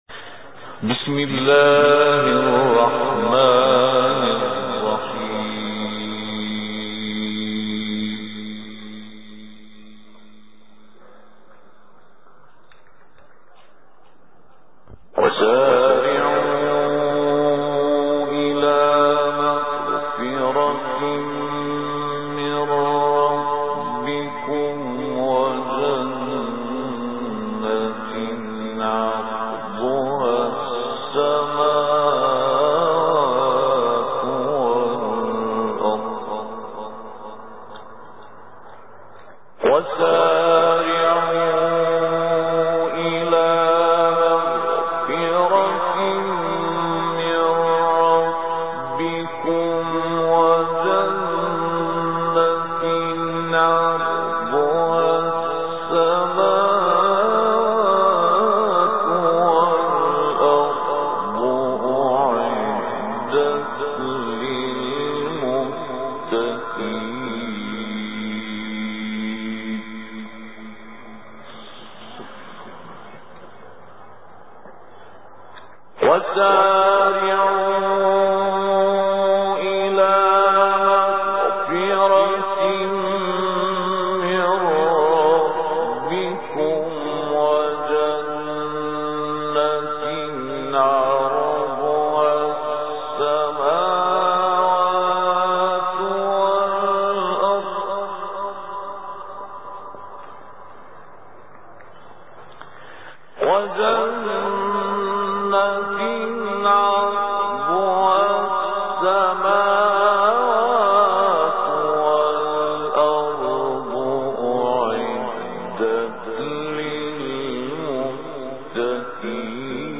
تلاوت آیاتی از سوره مبارکه آل عمران